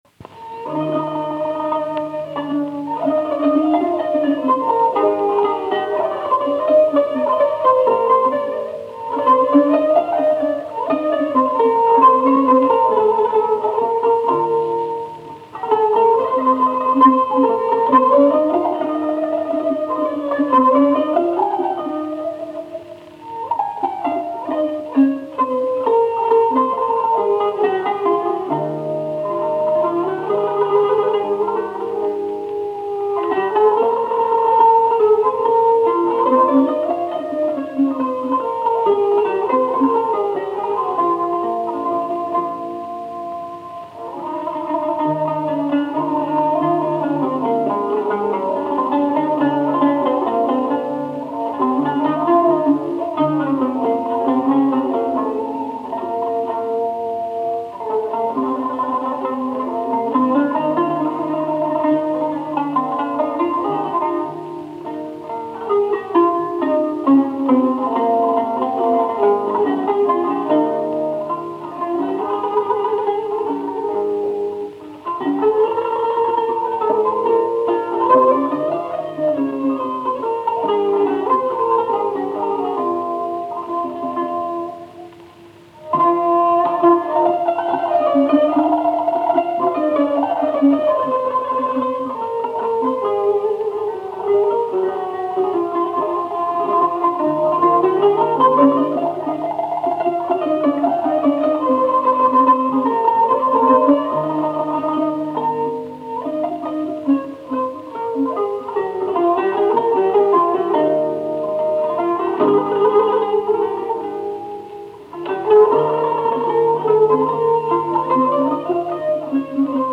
Genre: Turkish & Ottoman Classical.
a. Gerdaniye Pesrev, composed by Suphi Ezgi (1869-1962) b. Gerdaniye Saz Semaisi, composed by Suphi Ezgi c. Passing taksim: kemençe, kanun d. Hüseyni Oyun Havasi “Çeçen kizi”, composed by Tanburî Cemil Bey.
lavta